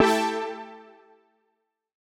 Index of /musicradar/future-rave-samples/Poly Chord Hits/Straight
FR_JPEGG[hit]-A.wav